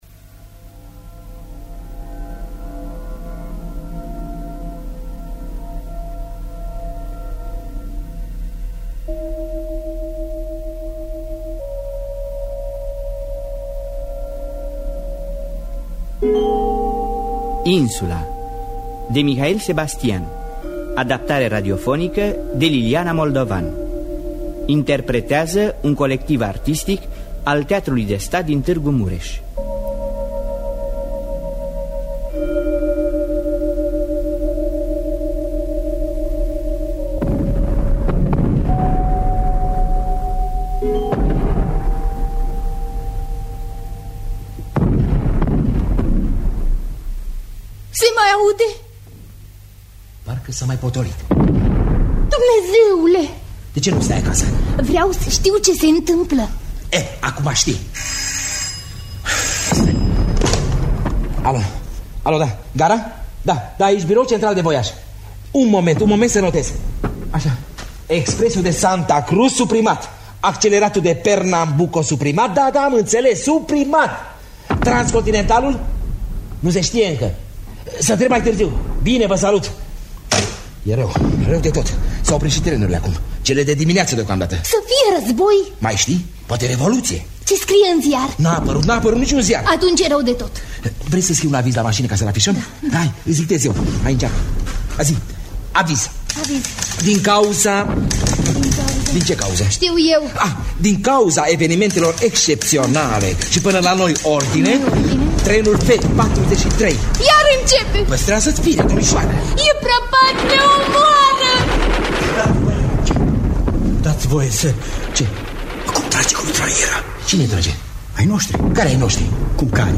Adaptare radiofonică